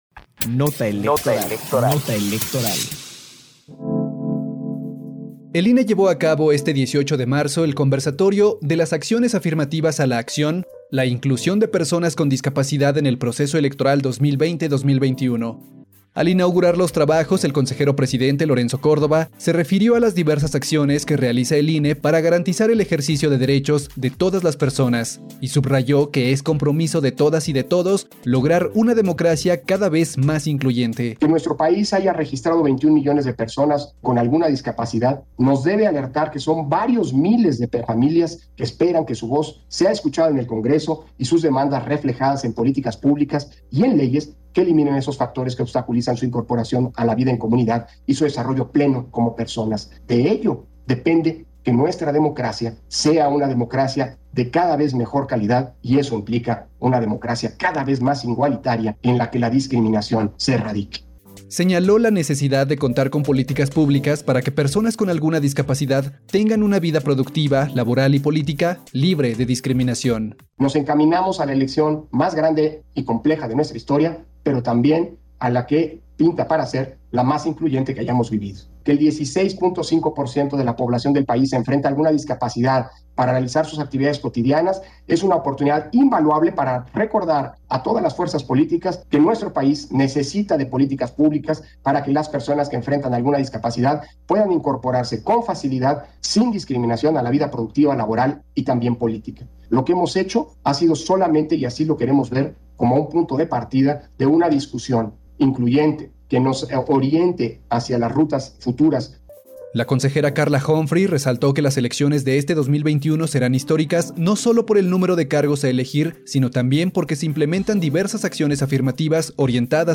PD_1041_CONVERSATORIO-ACCIONES-AFIRMATIVAS-DISCAPACIDADNOTA-ELEC_-18-marzo-2021